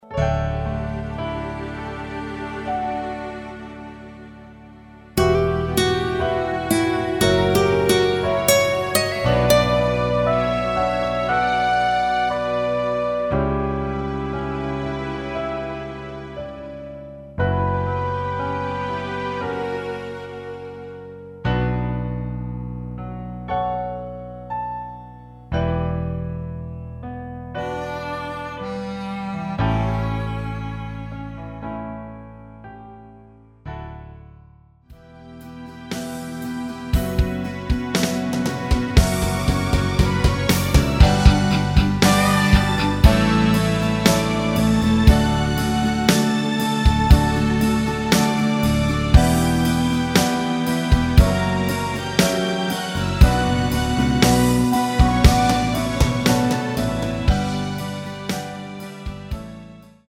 키 Bm 가수